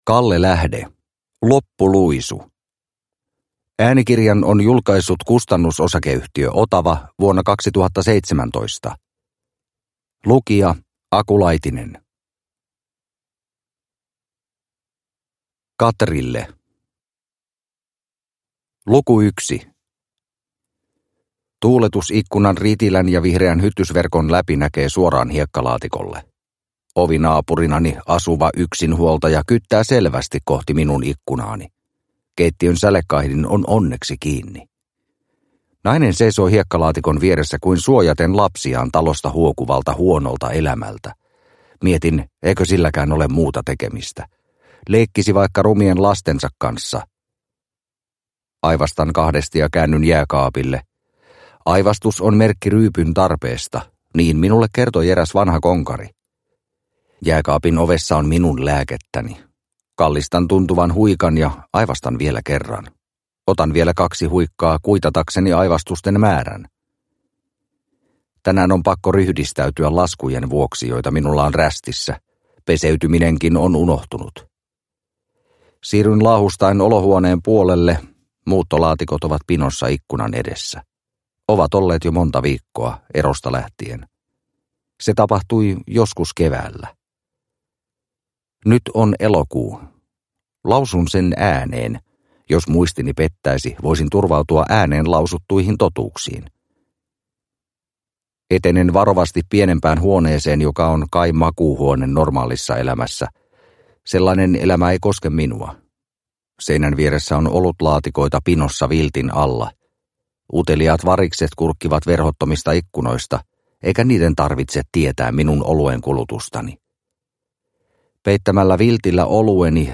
Loppuluisu – Ljudbok – Laddas ner